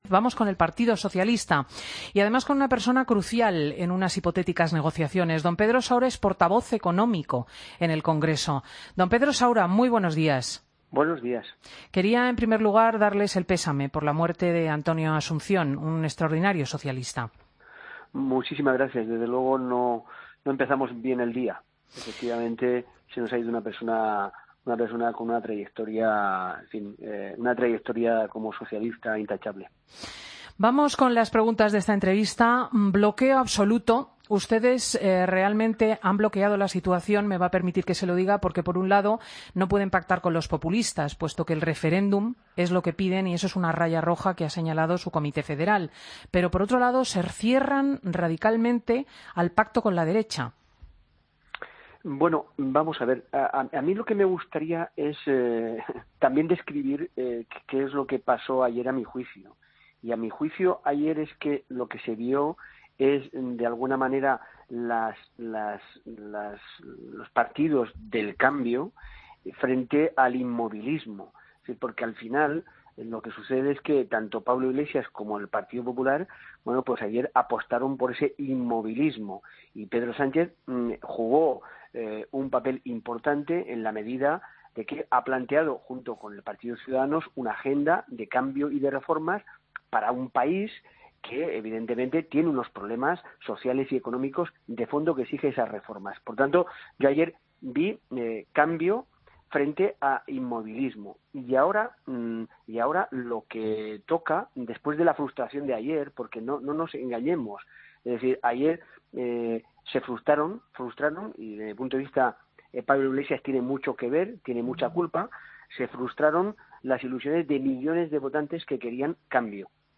Escucha la entrevista a Pedro Saura, Portavoz económico del PSOE en el Congreso, en Fin de Semana COPE